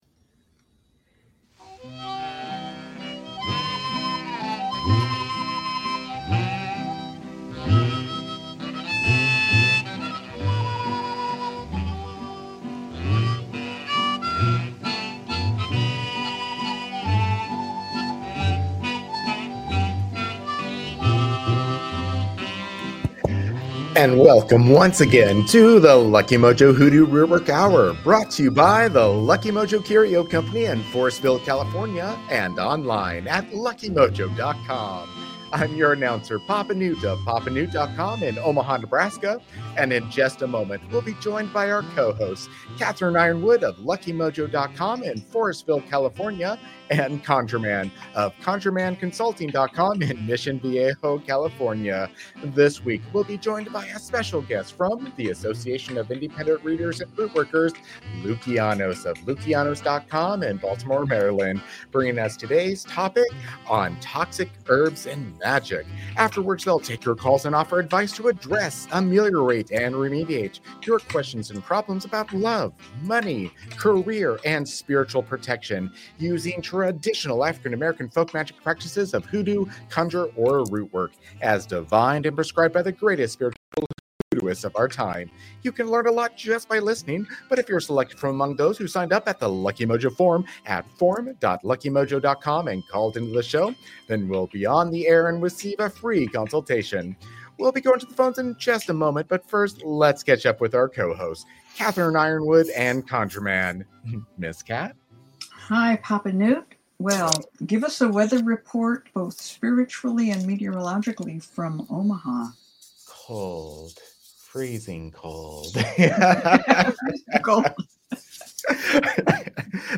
hoodoo conjure rootwork radio show